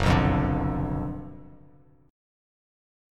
F#M7sus4#5 chord